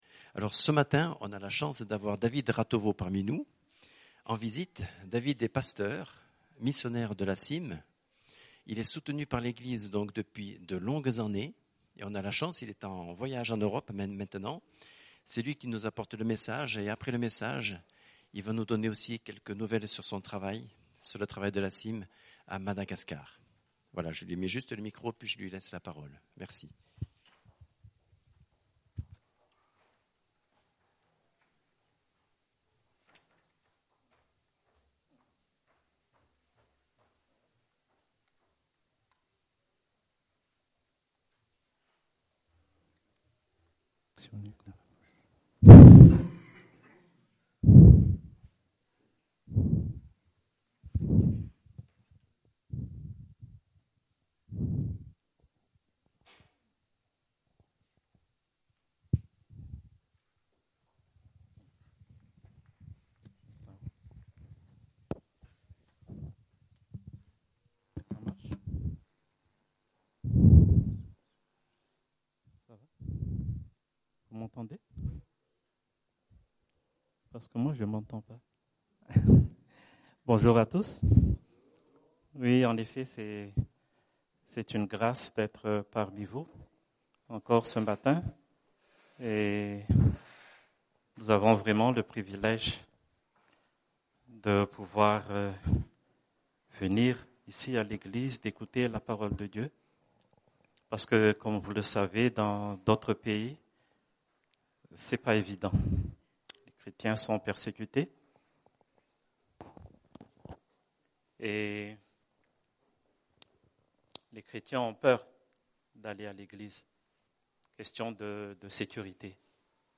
Preacher
Culte du 3 avril